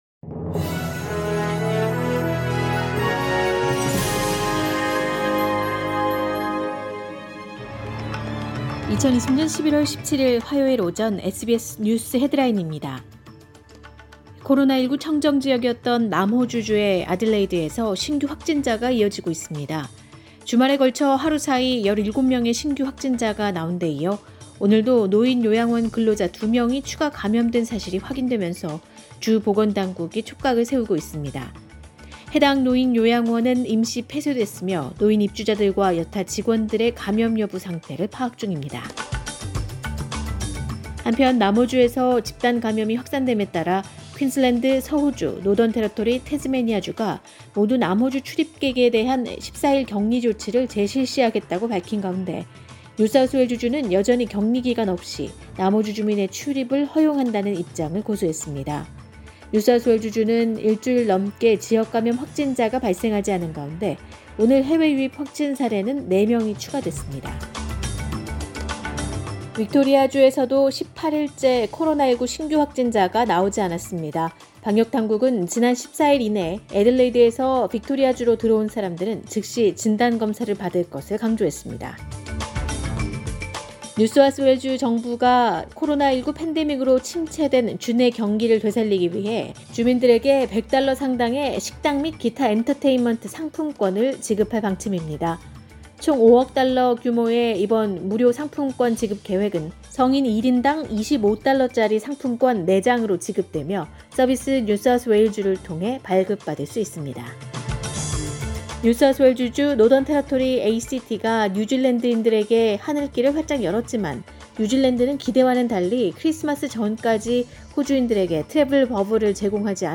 SBS News Headlines…2020년 11월 17일 오전 주요 뉴스
2020년 11월 17일 화요일 오전의 SBS 뉴스 헤드라인입니다.